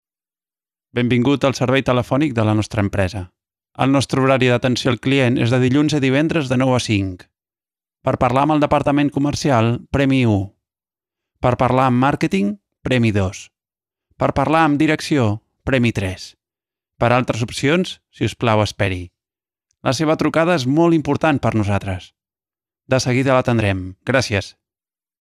Excellent quality, Speed, Versatile, Young voice - Middle age Excelente calidad, Rapidez, Versátil, Voz joven - Media edad
Sprechprobe: Sonstiges (Muttersprache):
Large diaphragm microphones for voice, Dynaudio near-field listening, Sound Devices preamp, Beyerdynamic headphones, Mackie Onyx table, and acoustically conditioned room